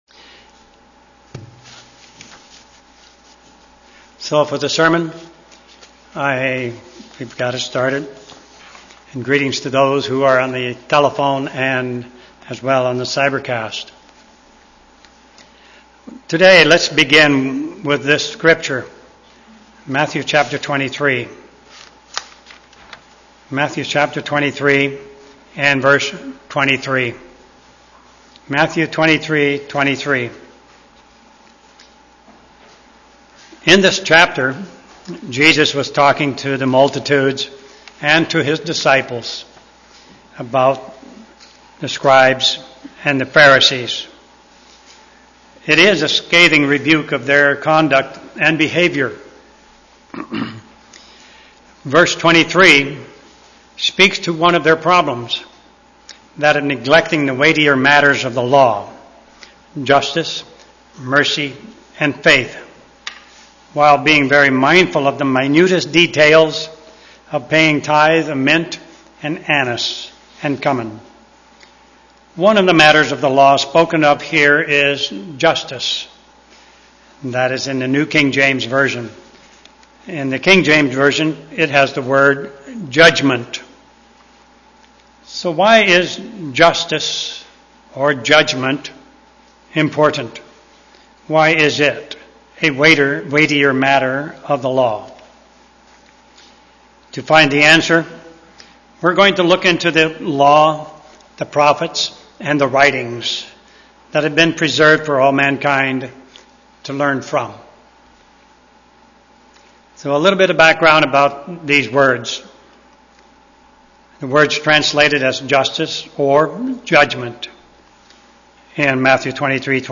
Given in Olympia, WA